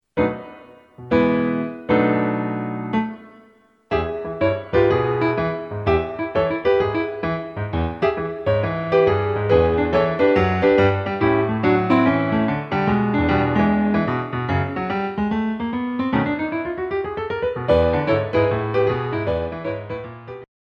A Ballet Class CD